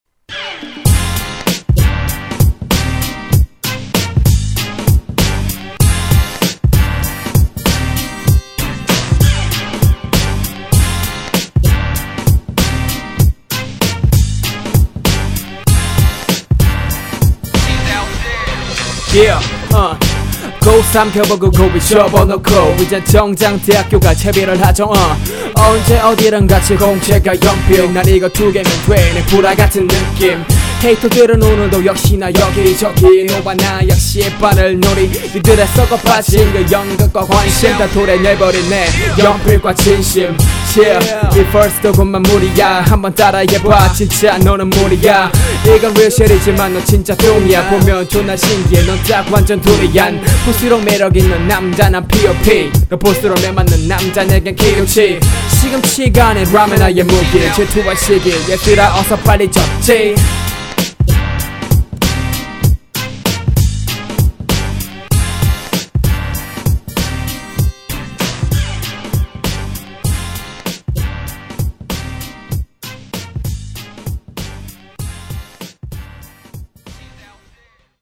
• [REMIX.]
전에비해 부정확해지신것같애요
벌스의 플로우나 라임의 구성이 많이 허술하다고 생각합니다